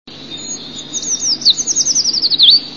Strofy śpiewu tego pełzacza są dłuższe niż u pełzacza ogrodowego i składają się z dwóch tryli zaczynających się wysoko i stopniowo opadających.
lesny.wav